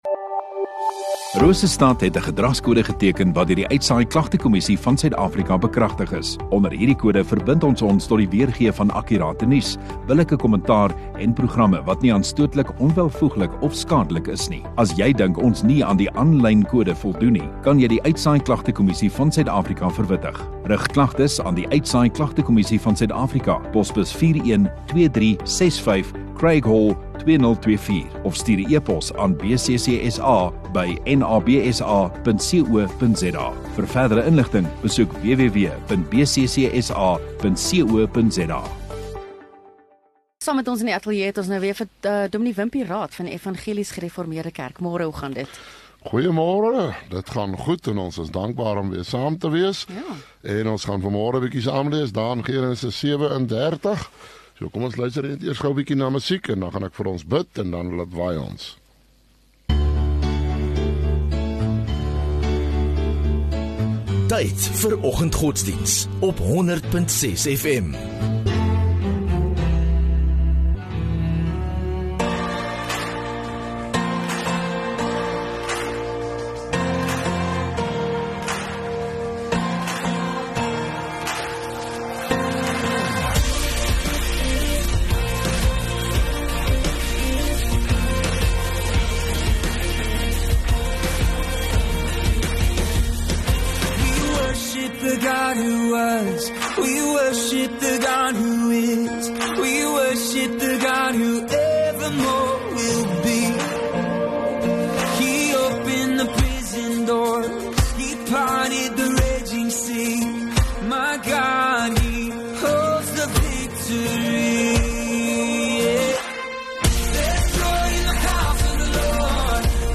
11 Jun Dinsdag Oggenddiens